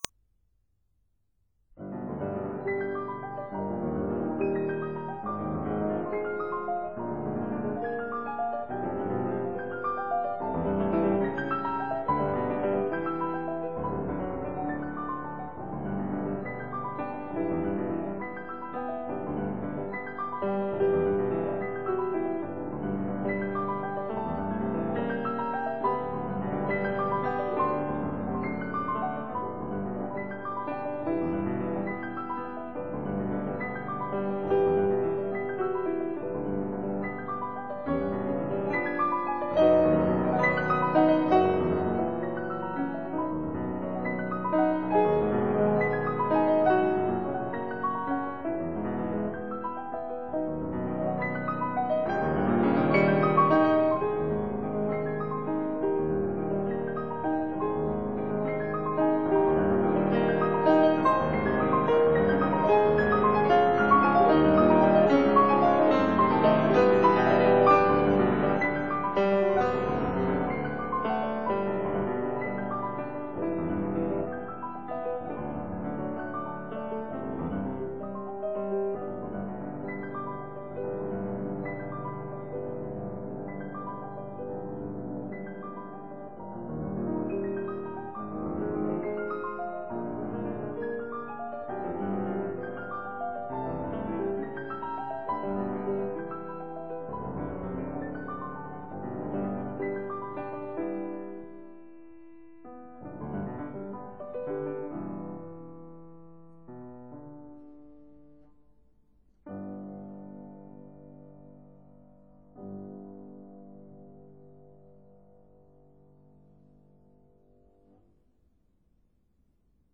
(2 pianos)